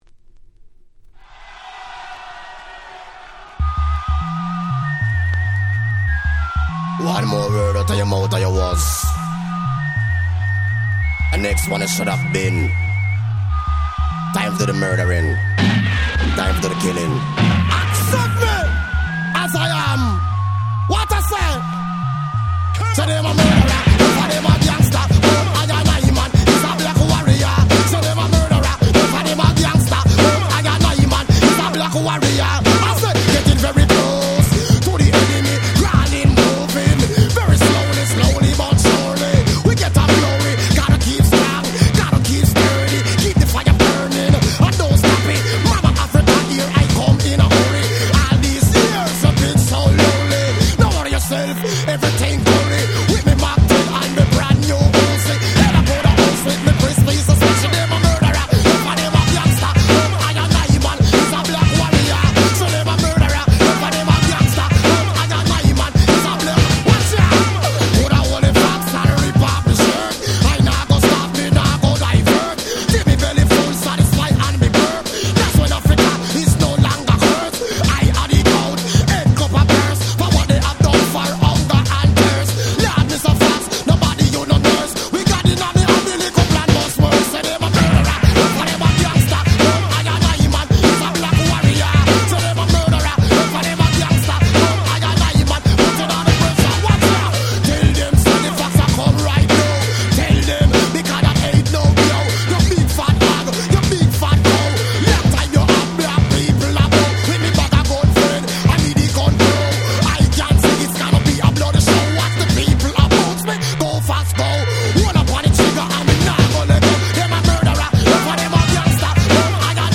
Dancehall Reggae